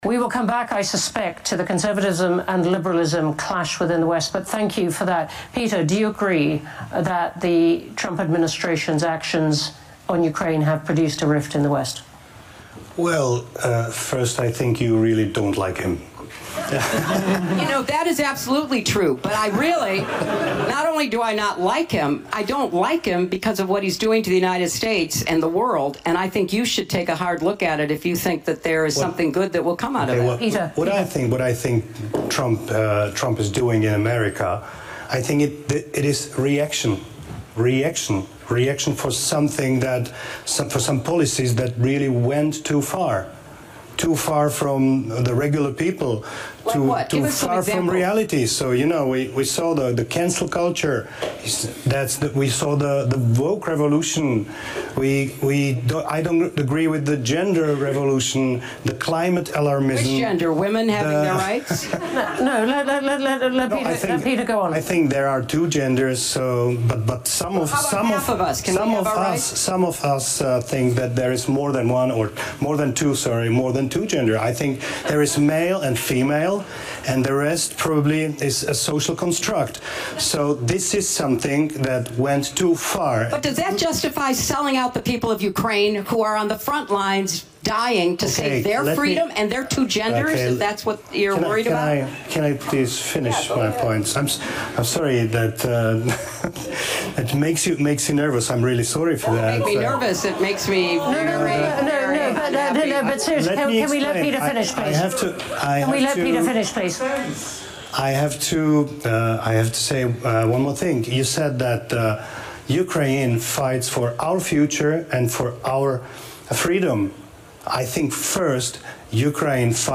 Former Secretary of State Hillary Clinton spoke at the Munich Security Conference in Germany on Saturday.